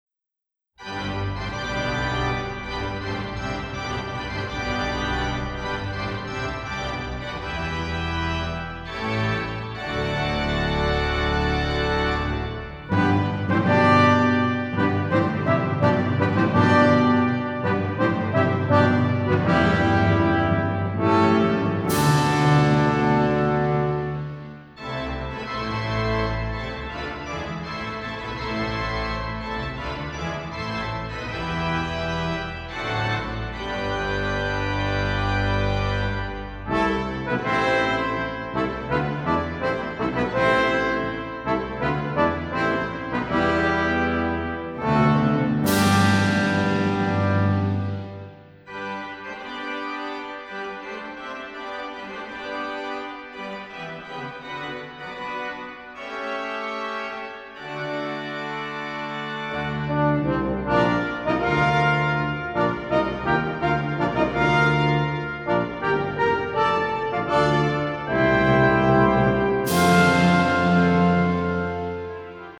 給管風琴、銅管及敲擊樂的作品
管風琴
古典音樂